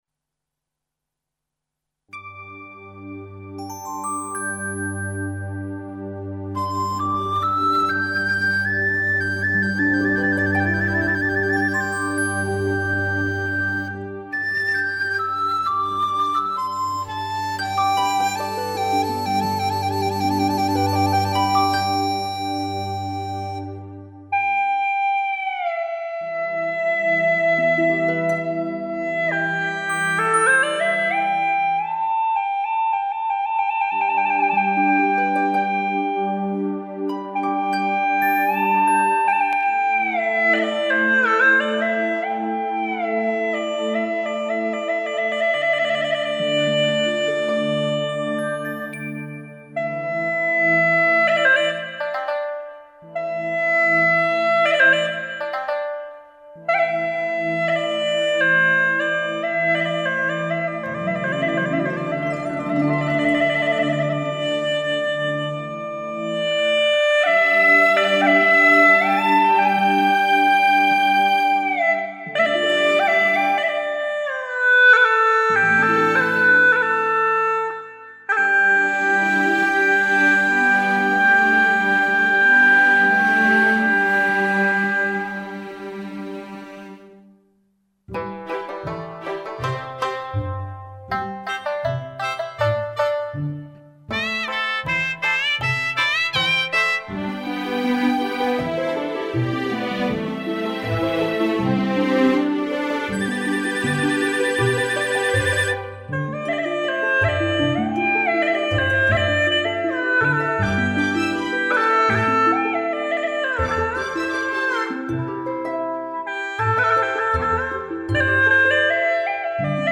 调式 : C 曲类 : 独奏
旋律优美，节奏欢快，深受丝友们喜爱。